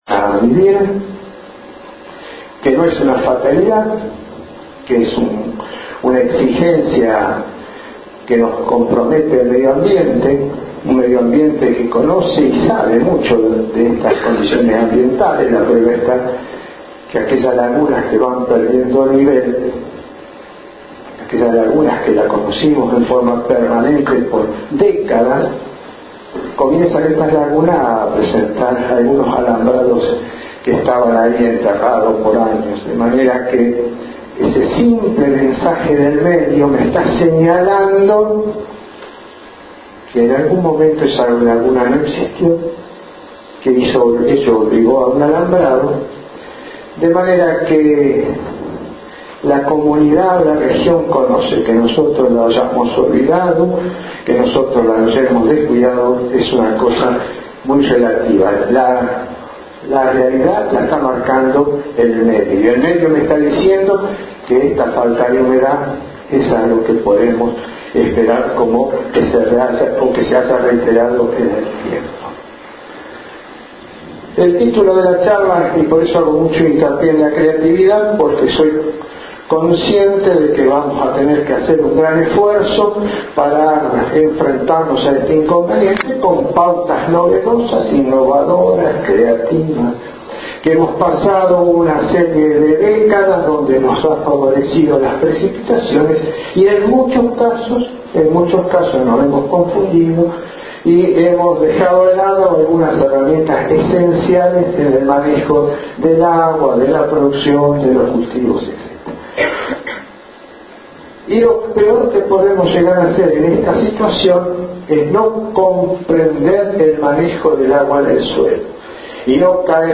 (La grabación es de "ambiente" por lo que pedimos disculpas por los defectos)